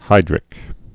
(hīdrĭk)